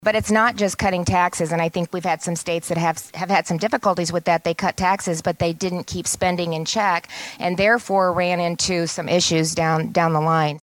Reynolds and the rest of the nation’s governors are meeting in Washington, D.C. this weekend and the governor made her comments at a forum sponsored by the Cato Institute, a libertarian think tank.